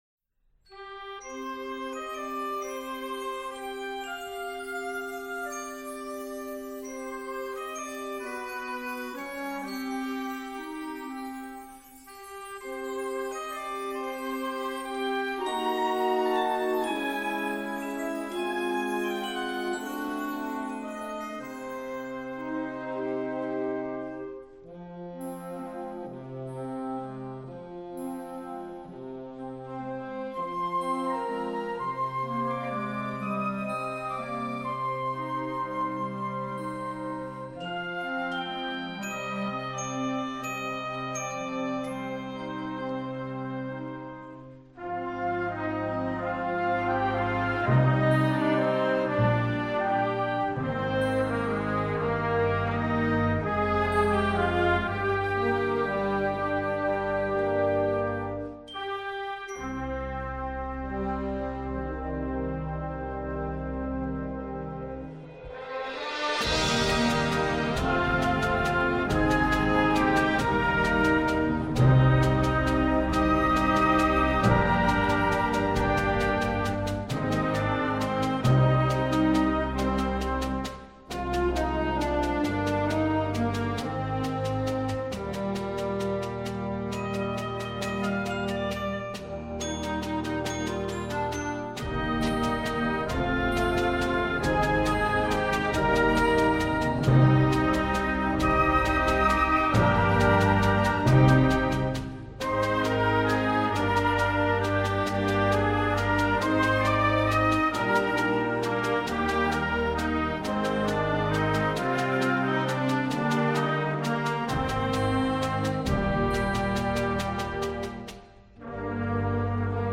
Gattung: Filmmusik für Jugendblasorchester
Besetzung: Blasorchester